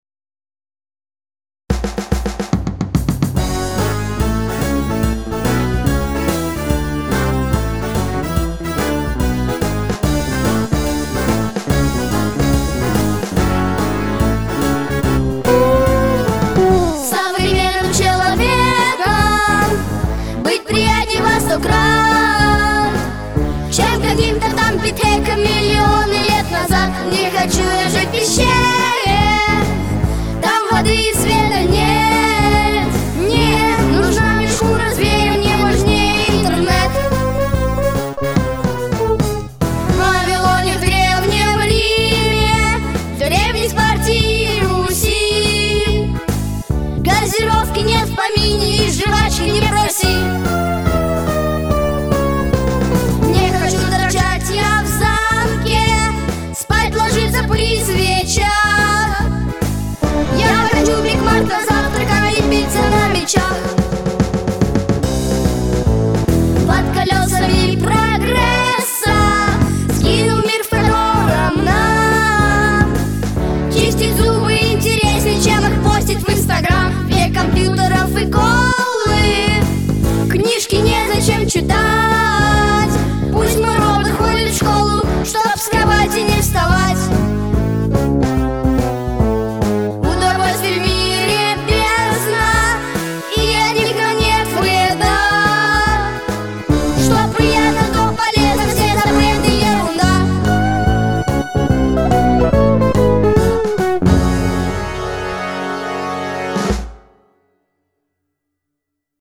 III Театральный Фестиваль начальной школы